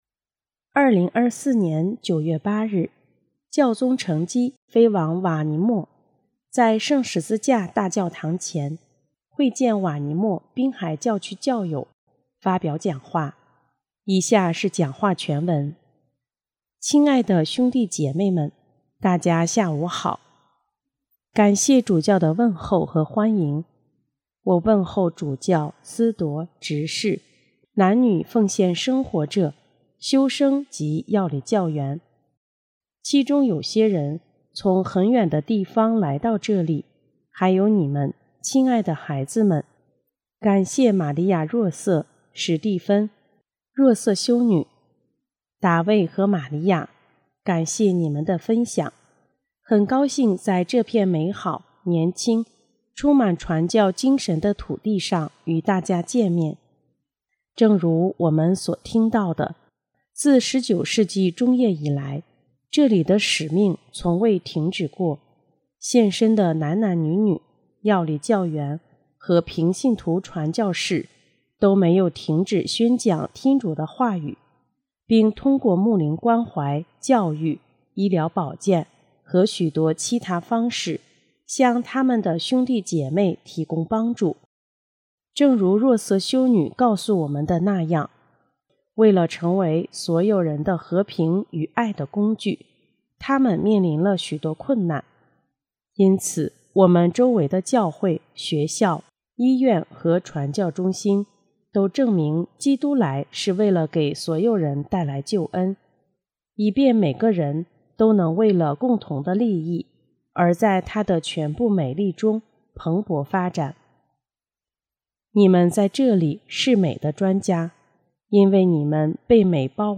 2024年的9月8日，教宗乘机飞往瓦尼莫，在圣十字大教堂前会见瓦尼莫滨海教区教友，发表讲话，以下是讲话全文：